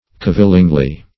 cavilingly - definition of cavilingly - synonyms, pronunciation, spelling from Free Dictionary Search Result for " cavilingly" : The Collaborative International Dictionary of English v.0.48: Cavilingly \Cav"il*ing*ly\, adv.
cavilingly.mp3